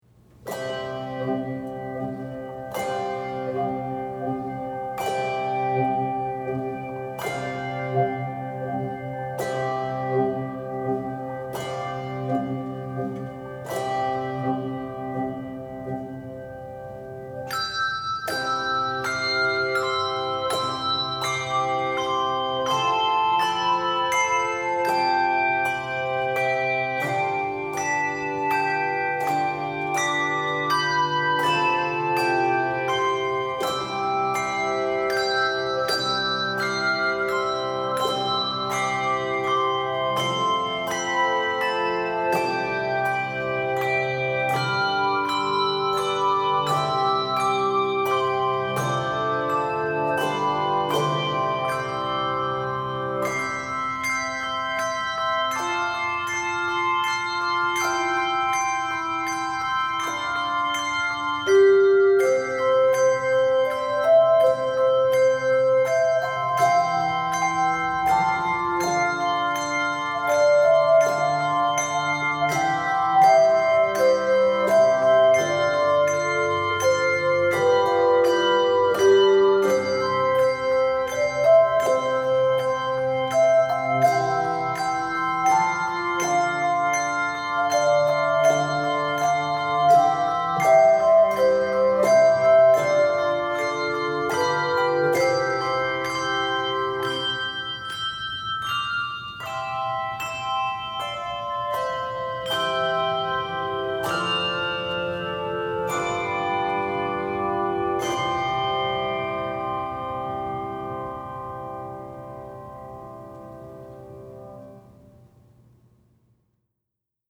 Voicing: Handbells